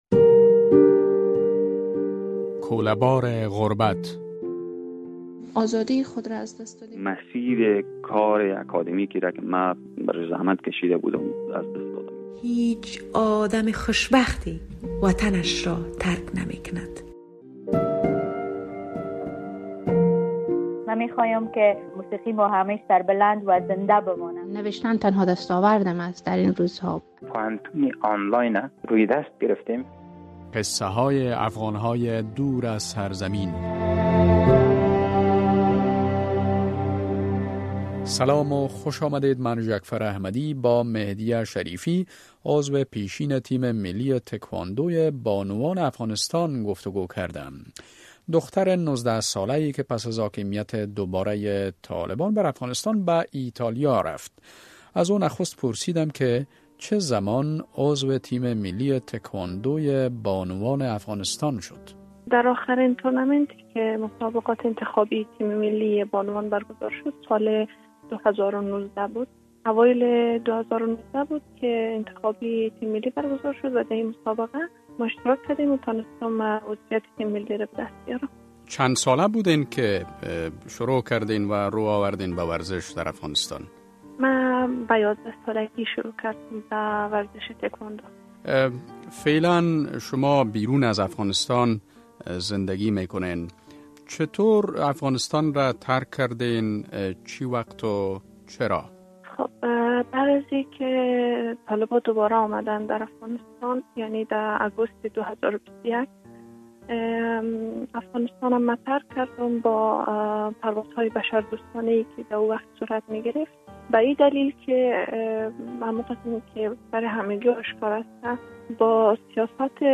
رادیو آزادی سلسله ای از گفتگو های جالب با آن عده از شهروندان افغانستان را آغاز کرده است که پس از حاکمیت دوبارۀ طالبان بر افغانستان، مجبور به ترک کشور شده اند.